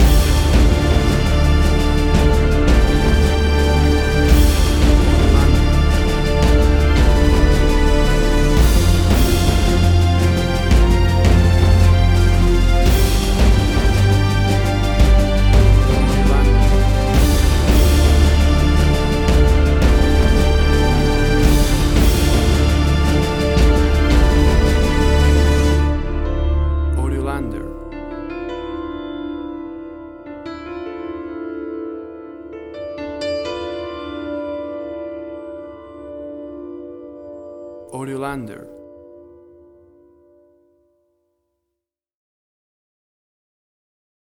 WAV Sample Rate: 16-Bit stereo, 44.1 kHz
Tempo (BPM): 112